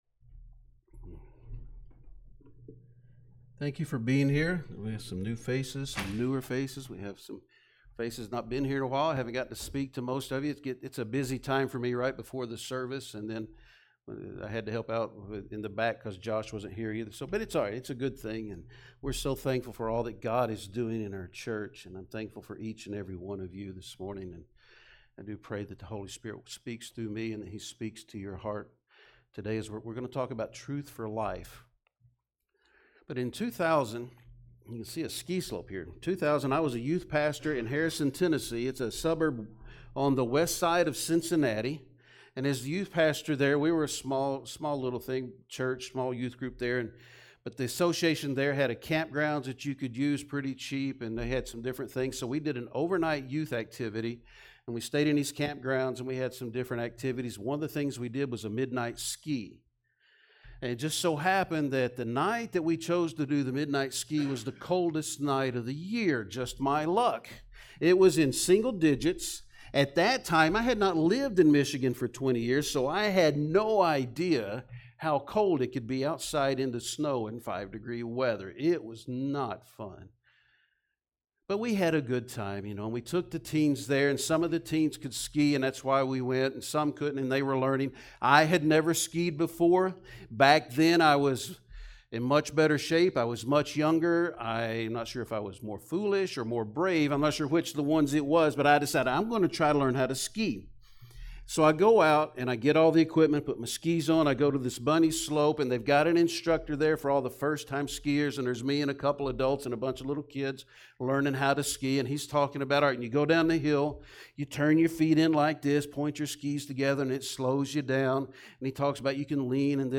Sermons | Summitville First Baptist Church